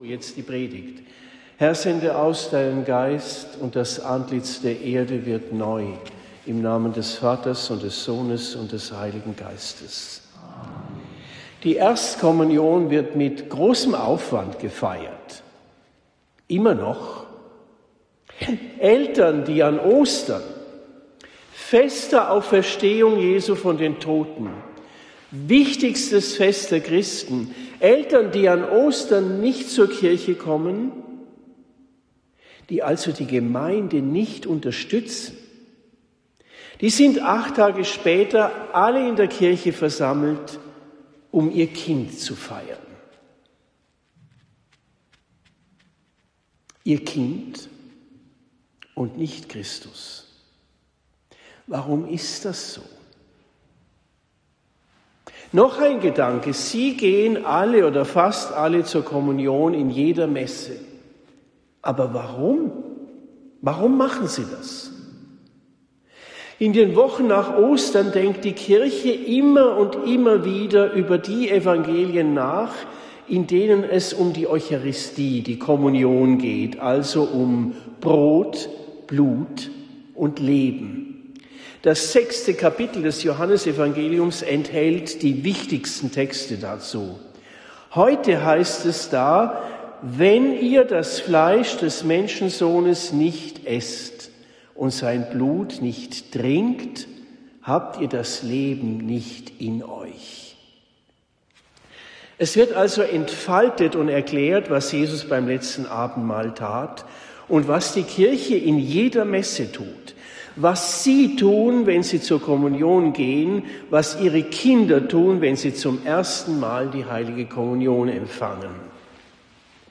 Predigt in Hafenlohr am 24. April 2026